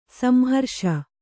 ♪ samharṣa